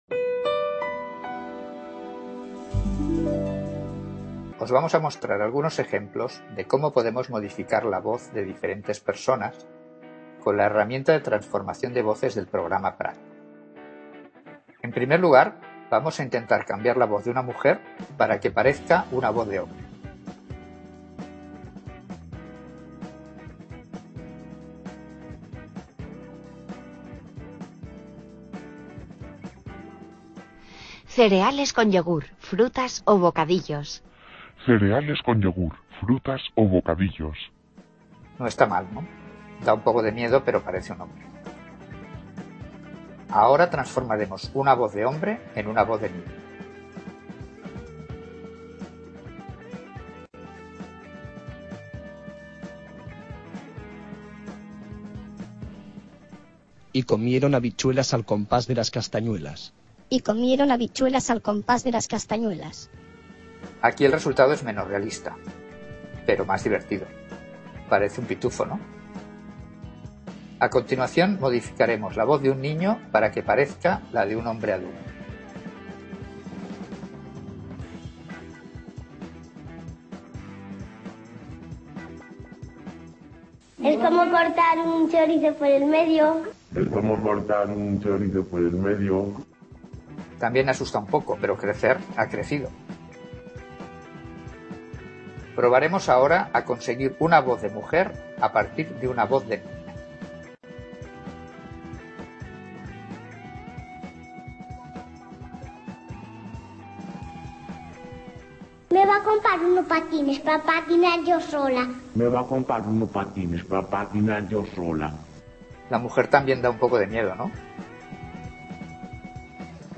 Demostración transformación de voces.
Video Clase